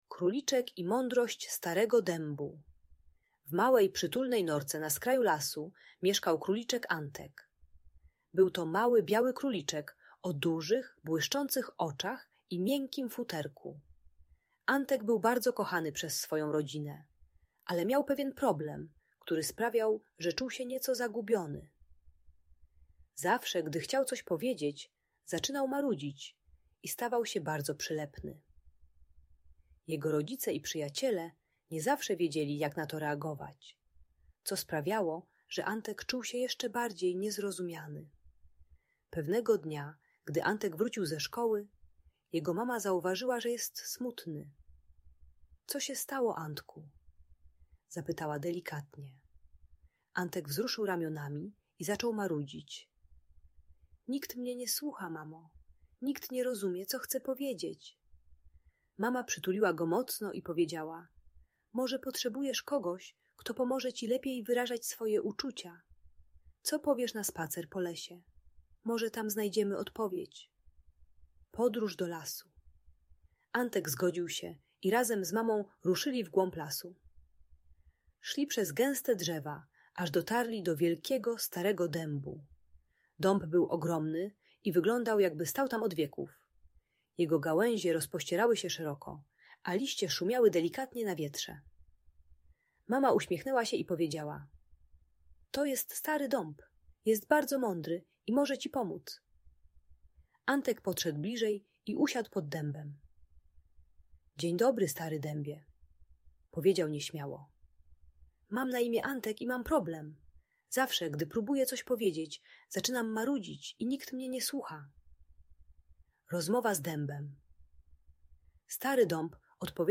Opowieść o Króliczku Antku - Audiobajka dla dzieci